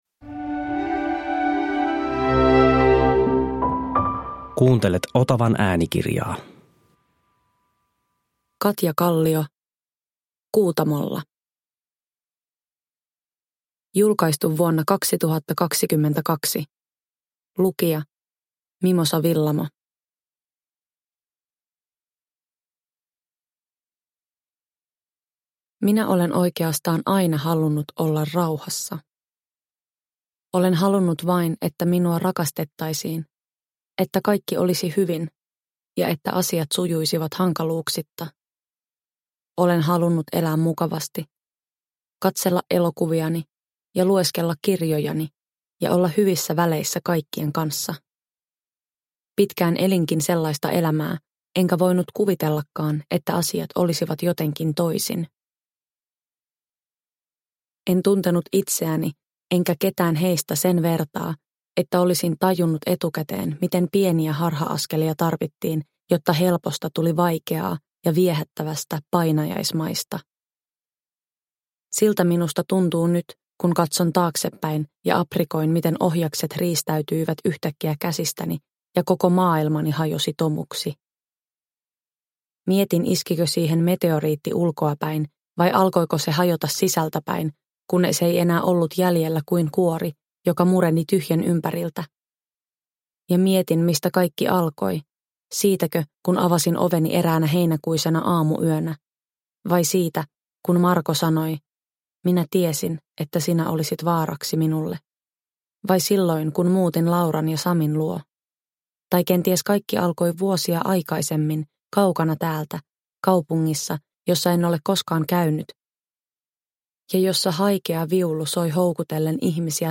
Kuutamolla – Ljudbok – Laddas ner
Uppläsare: Mimosa Willamo